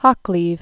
(hŏklēv) or Oc·cleve (ŏk-), Thomas 1369?-1450?